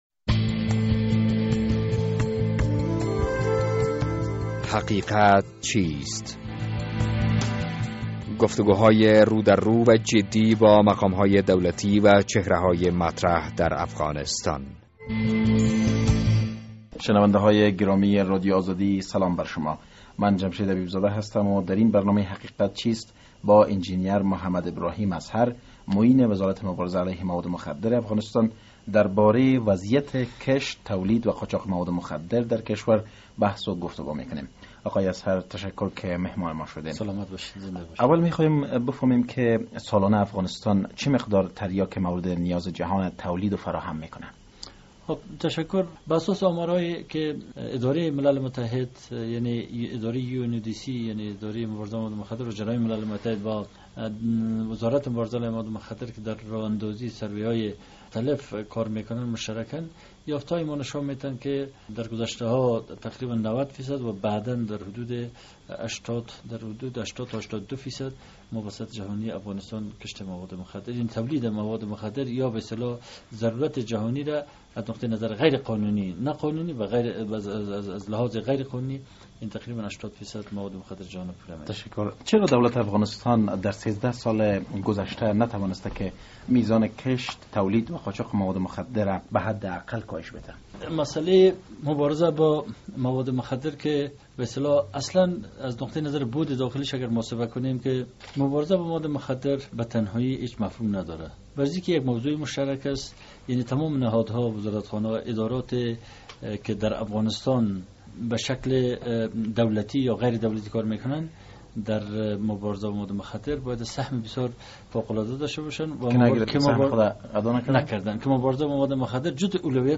در این برنامه حقیقت چیست با انجنیر محمد ابراهیم ازهر معین وزارت مبارزه علیه مواد مخدر افغانستان گفتگو کرده ایم...